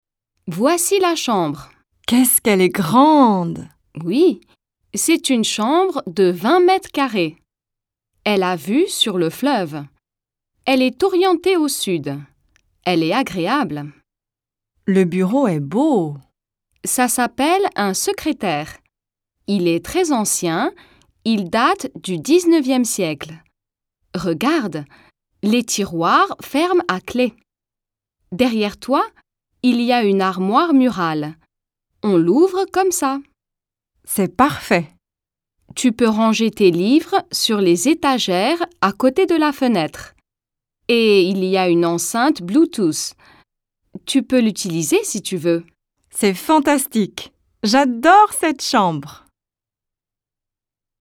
Lesson: Understanding a French Room Tour Dialogue